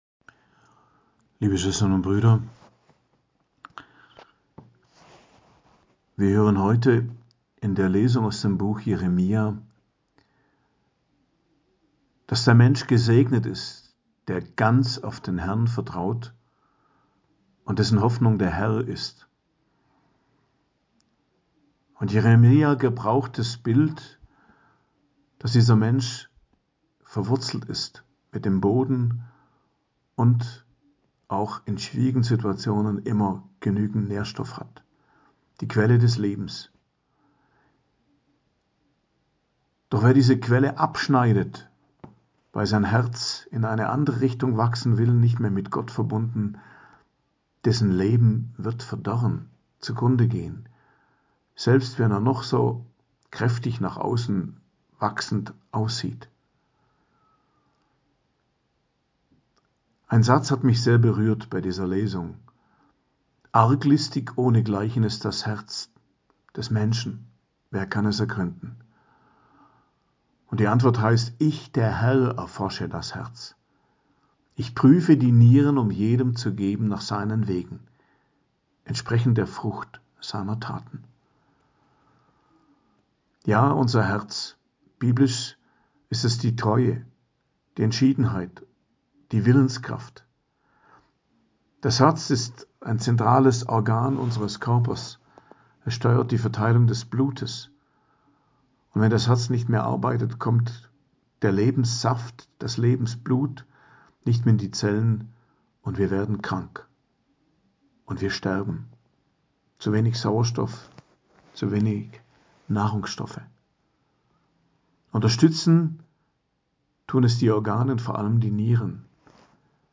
Predigt am Donnerstag der 2. Woche der Fastenzeit, 29.02.2024